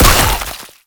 spitter-death-2.ogg